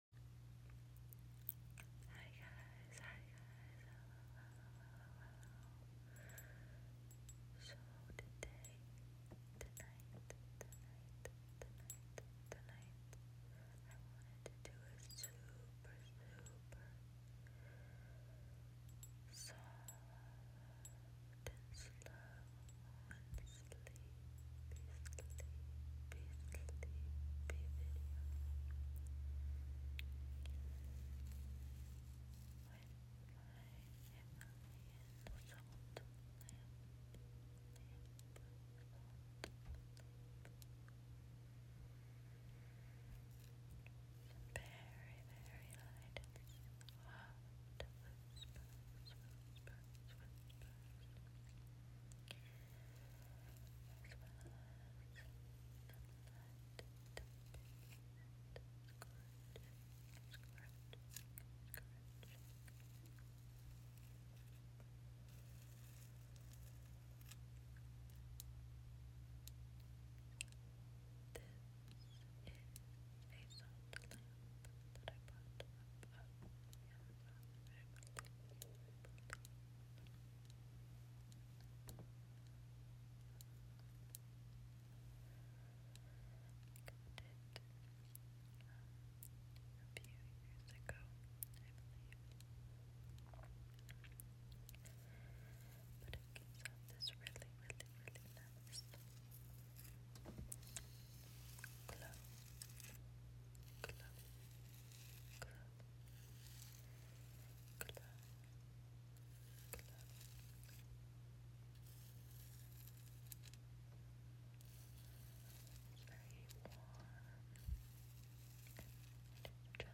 super sleepy lofi asmr whispers, sound effects free download
super sleepy lofi asmr whispers, tapping, and scratching!